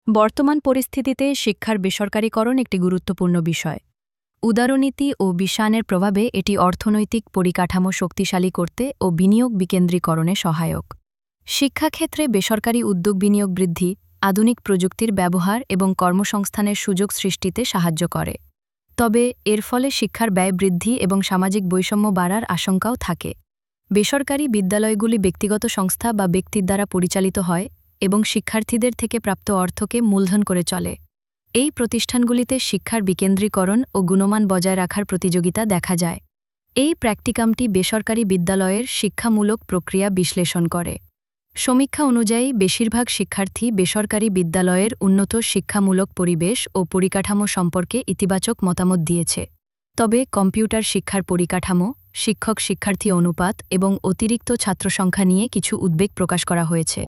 A short audio explanation of this file is provided in the video below.